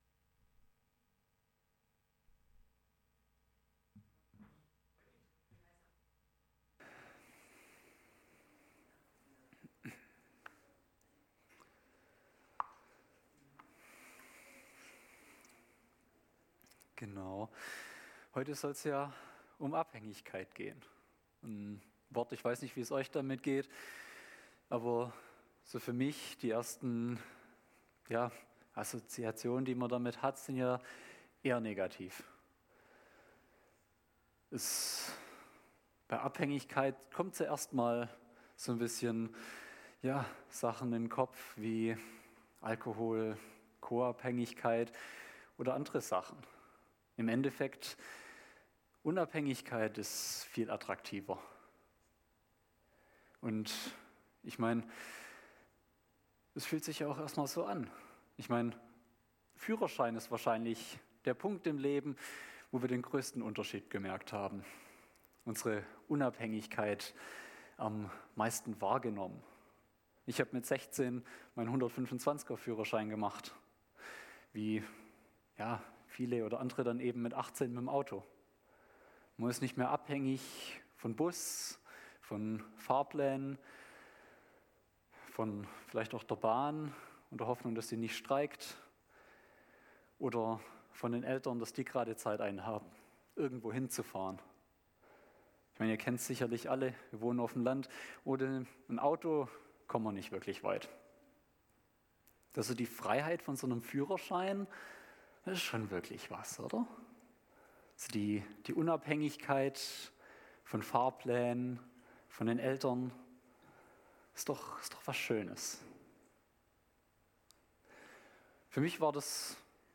Gottesdienst am 28.04.2024